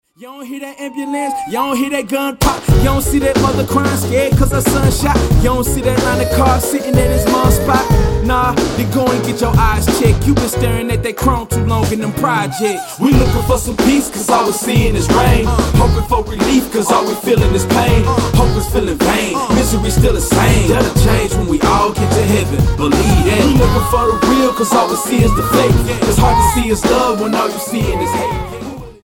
STYLE: Hip-Hop
old skool uplifting groove